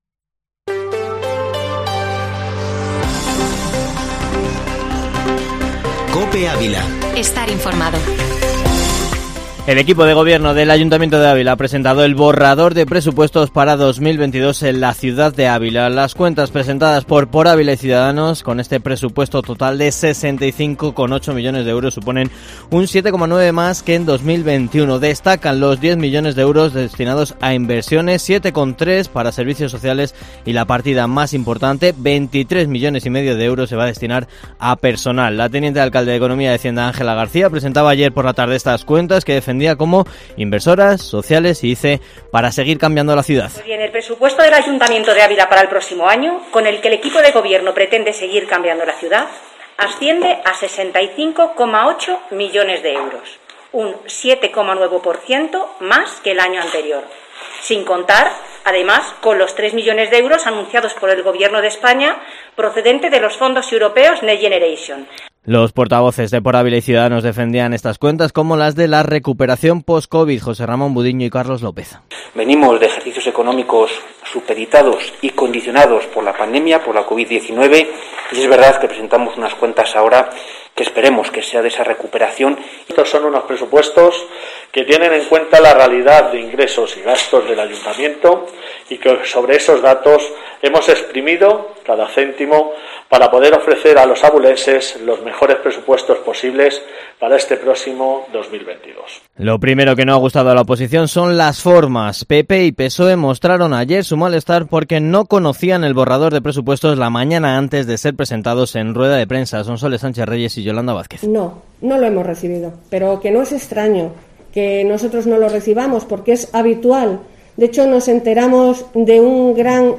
Informativo Matinal Herrera en COPE Ávila -24-nov